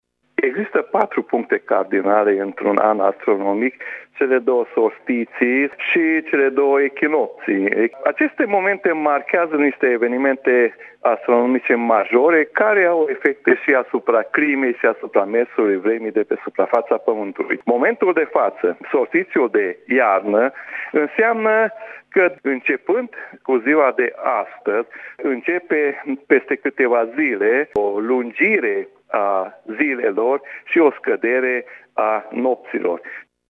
Ne explică fenomenul meteorologul mureșean